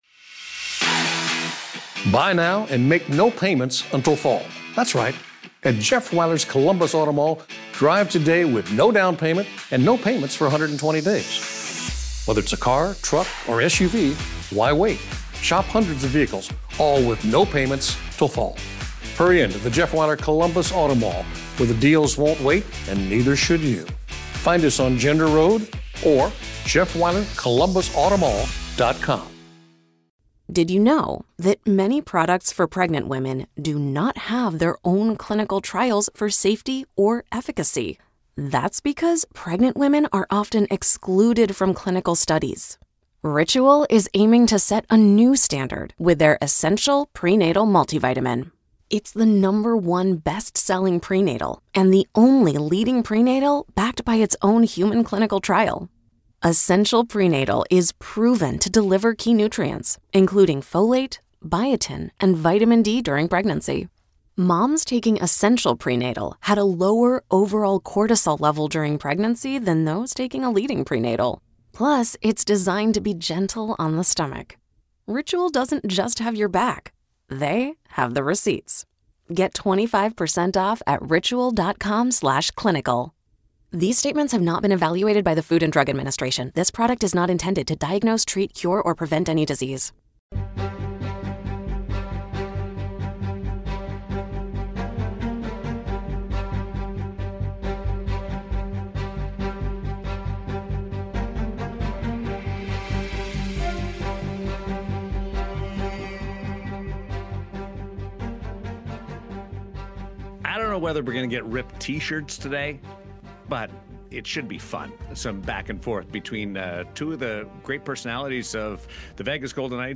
It’s an impromptu hockey roundtable
From roster strengths to playoff potential, it's a spirited debate you won’t want to miss!